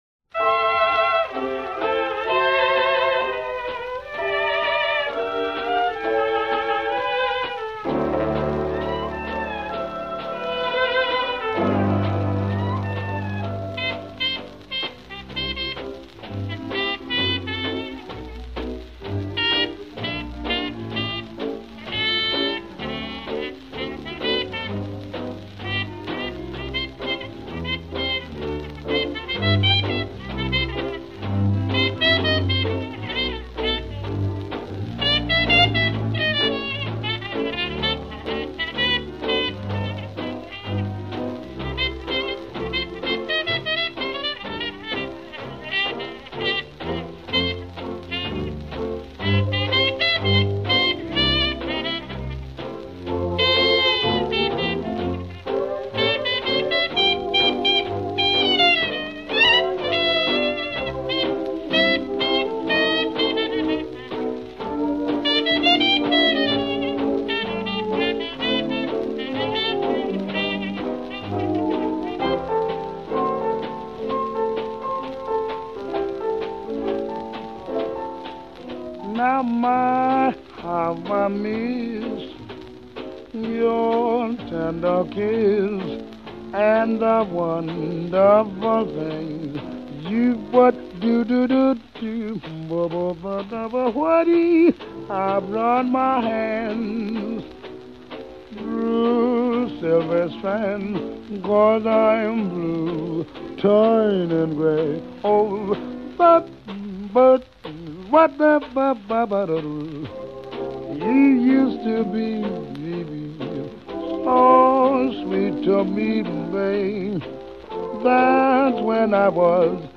1929 jazz standard.